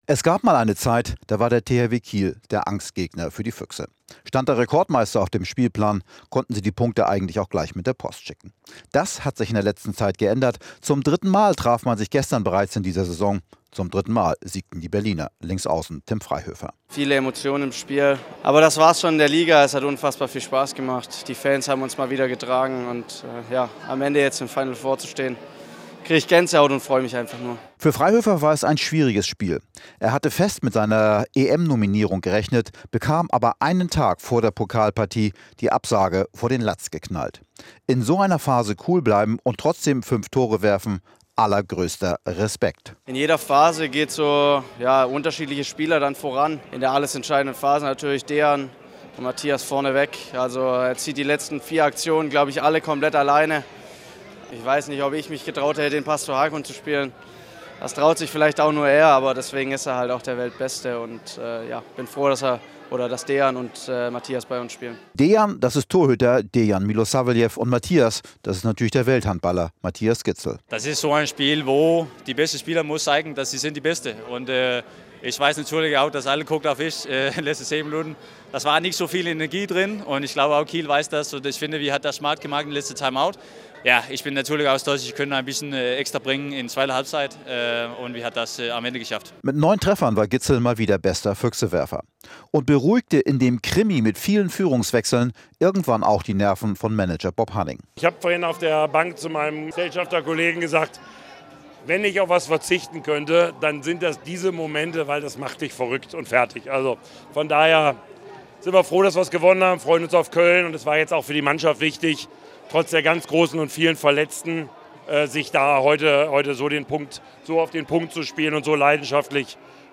In Interviews und Reportagen blicken wir auf den Sport in der Region und in der Welt.